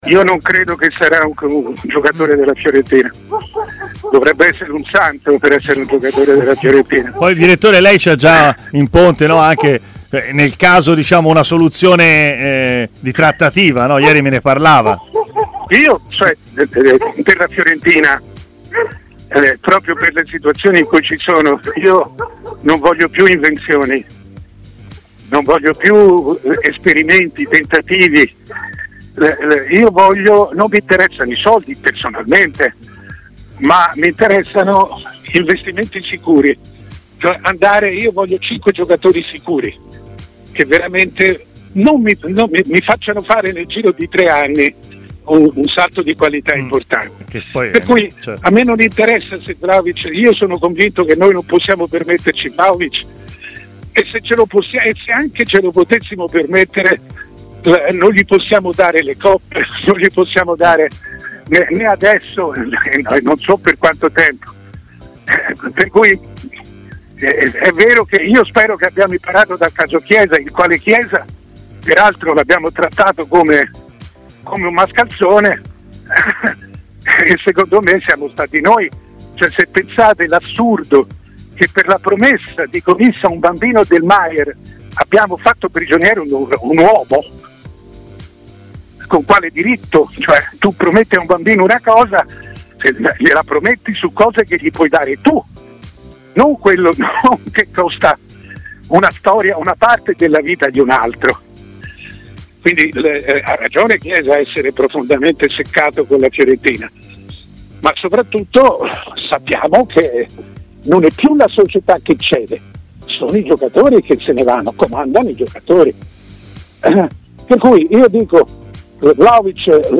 Mario Sconcerti, firma di punta del giornalismo sportivo italiano e opinionista di TMW Radio, è intervenuto durante Stadio Aperto.